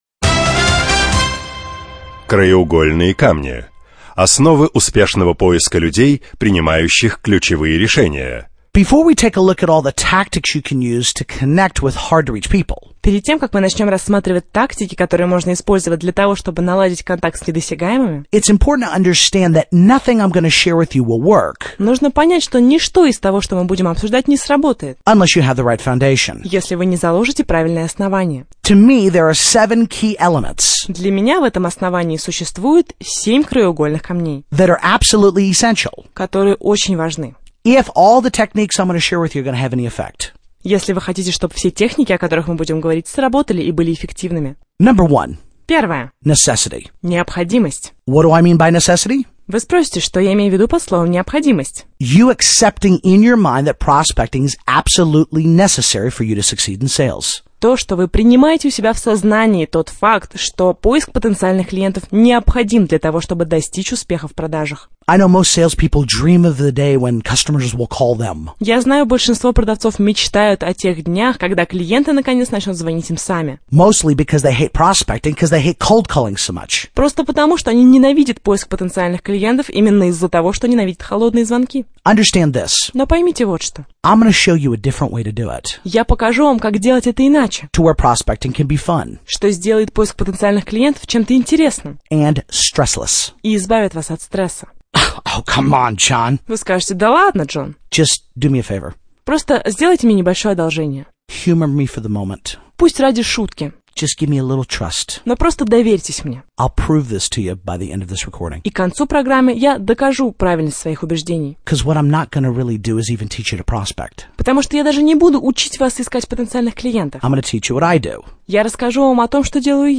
Аудиокнига: Недосягаемые